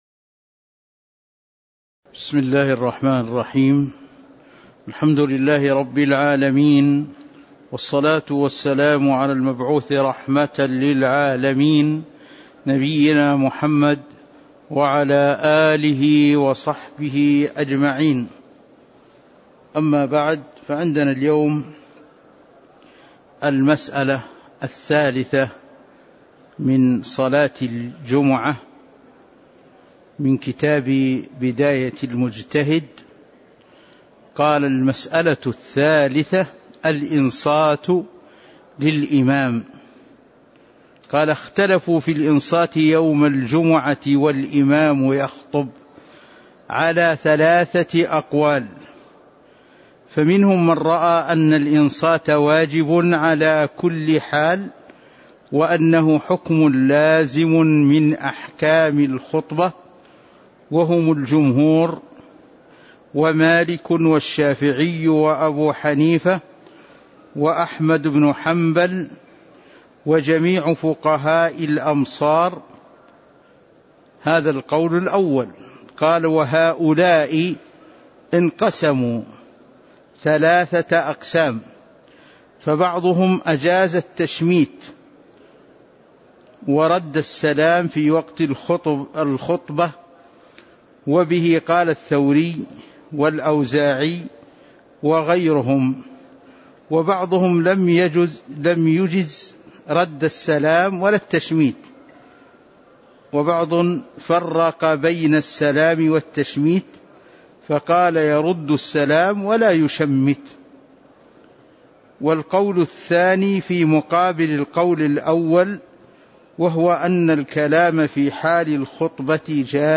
تاريخ النشر ٢٩ جمادى الأولى ١٤٤٣ هـ المكان: المسجد النبوي الشيخ